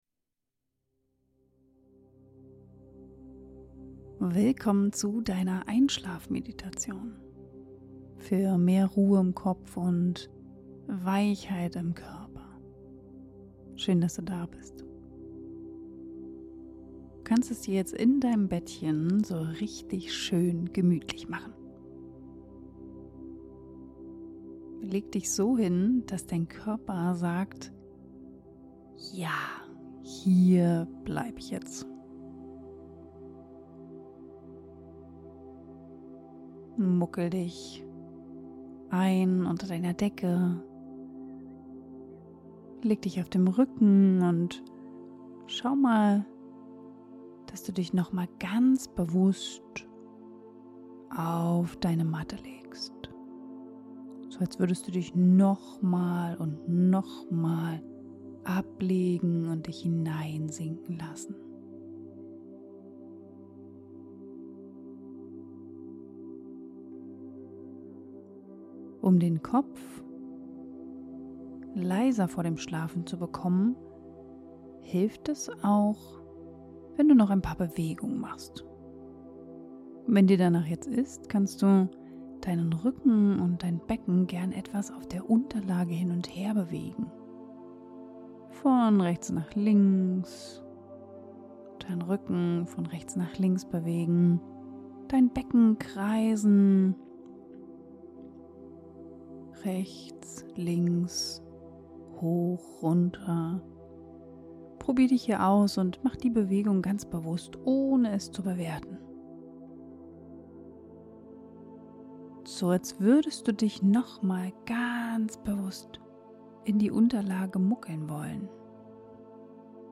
Diese Meditation ist für dich, wenn dein Kopf abends noch kreiselt, dein Körper unruhig ist oder du einfach nicht richtig zur Ruhe kommst. Mach sie am besten direkt im Bett – dort, wo du nichts mehr tun musst.